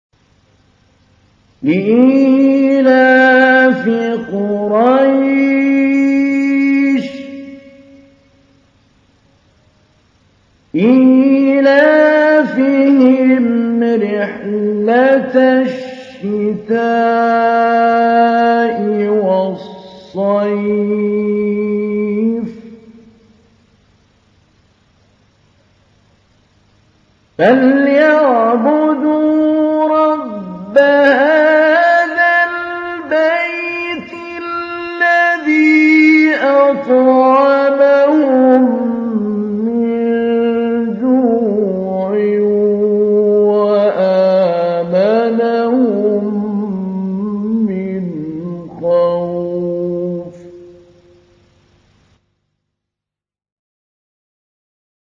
تحميل : 106. سورة قريش / القارئ محمود علي البنا / القرآن الكريم / موقع يا حسين